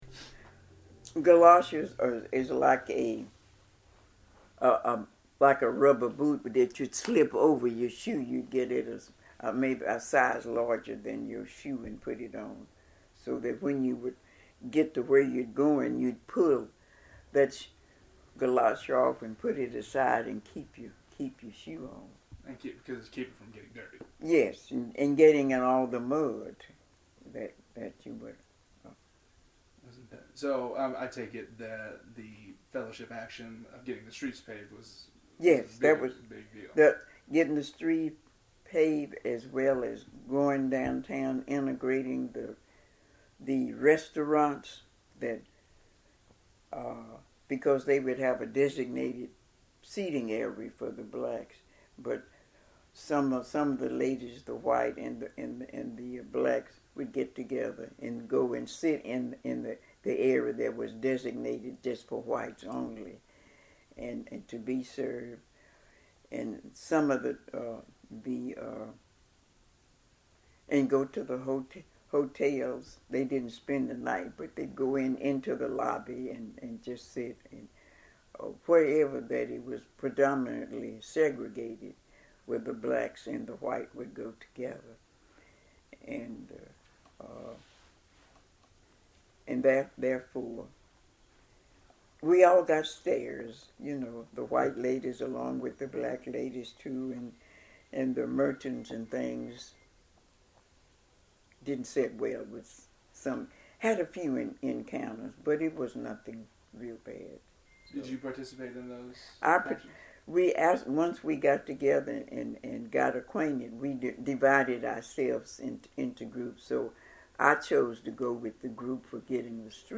UNT Oral History Interview